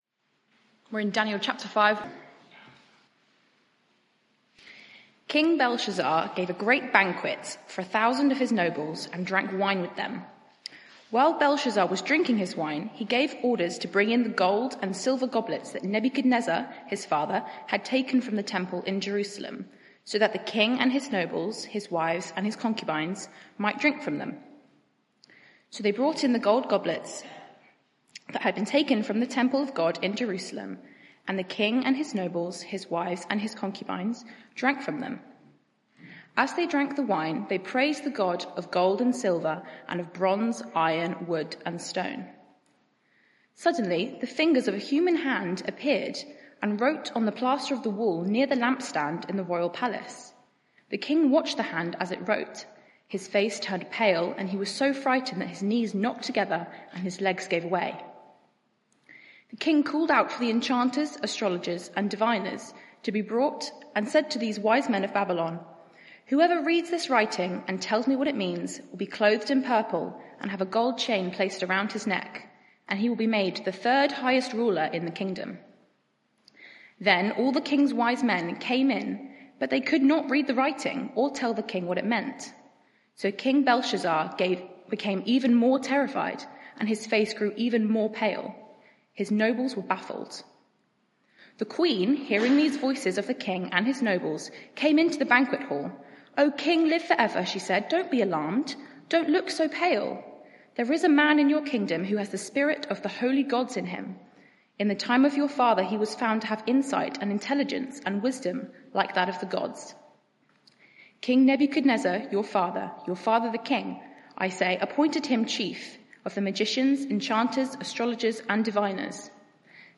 Media for 6:30pm Service on Sun 04th Jun 2023 18:30 Speaker
Daniel 5 Sermon (audio) Search the media library There are recordings here going back several years.